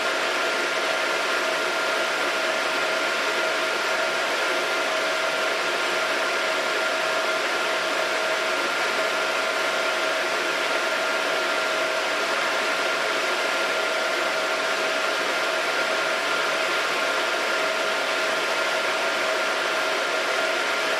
At full speed, the highest frequency is 630 Hz, and the range between 1.25 KHz and 1.6 KHz is also high.
I have recorded the signals shown above, but please keep in mind that I’ve enabled Automatic Gain Control (AGC) to do so to make it easier for you to reproduce them.
100% Fan Speed